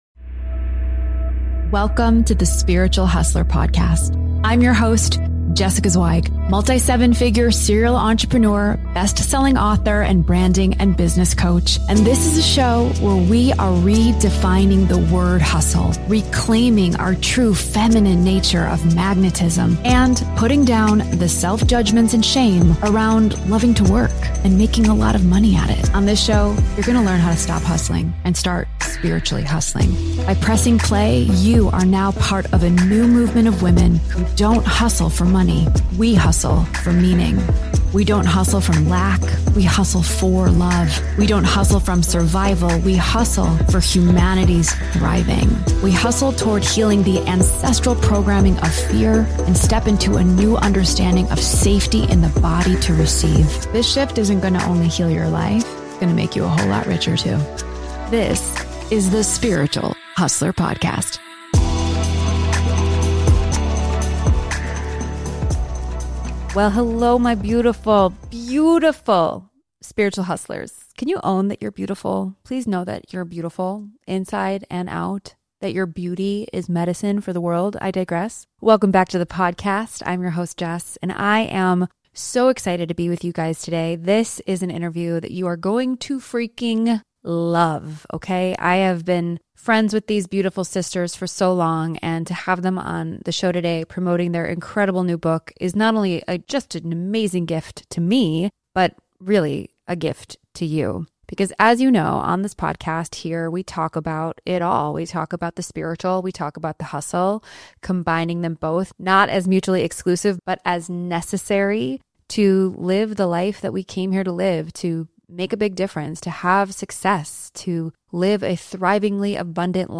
Through raw solo episodes and captivating interviews with the most inspiring female leaders of today, you will discover what it looks like to hustle from a new vibration of love, trust, meaning and service to humanity, and how it can heal your life.